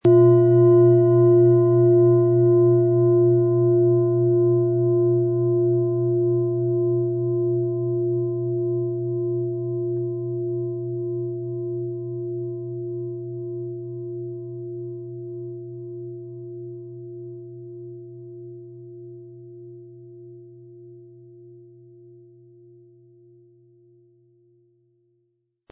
• Mittlerer Ton: Hopi-Herzton
Sie möchten den schönen Klang dieser Schale hören? Spielen Sie bitte den Originalklang im Sound-Player - Jetzt reinhören ab.
PlanetentöneSonne & Hopi-Herzton
HerstellungIn Handarbeit getrieben
MaterialBronze